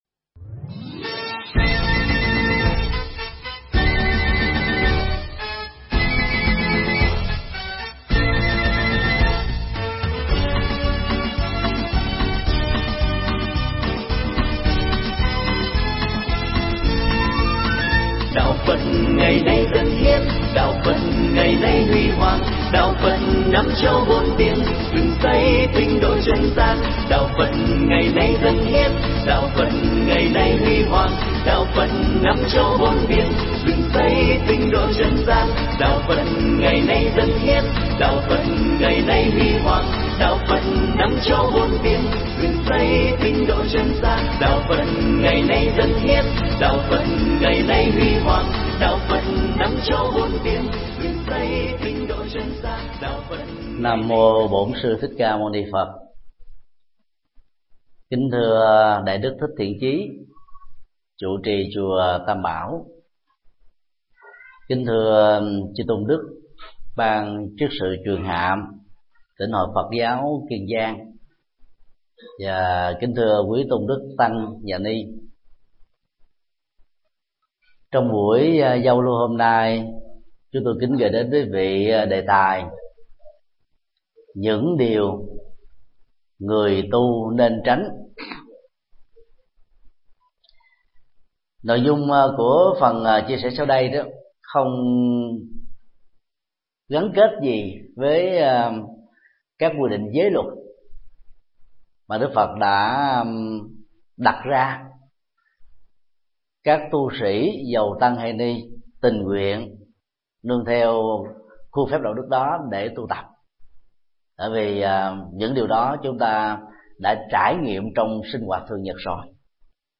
Pháp thoại Những điều người tu sĩ không nên làm
giảng tại chùa Tam Bảo, Rạch Giá, Kiên Giang